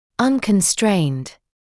[ˌʌnkən’streɪnd][ˌанкэн’стрэйнд]не имещий ограничений; несдерживаемый; непринужденный